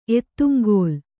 ♪ ettuŋgōl